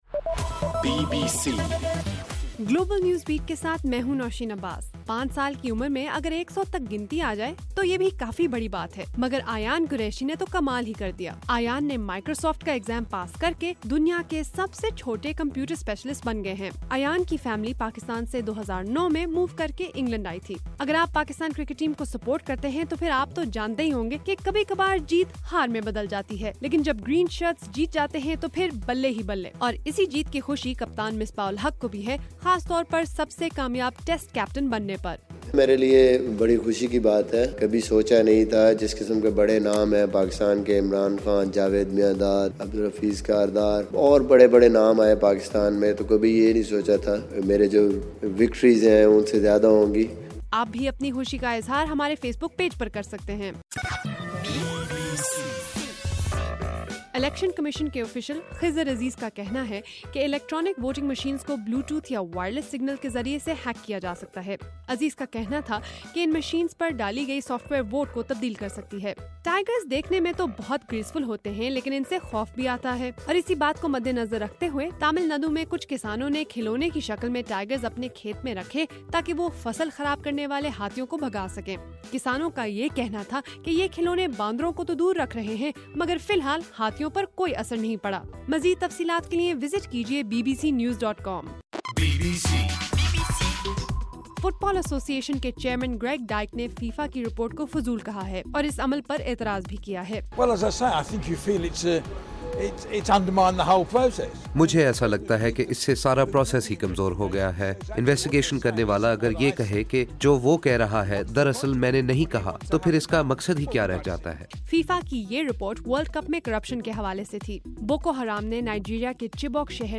نومبر 18: رات 12 بجے کا گلوبل نیوز بیٹ بُلیٹن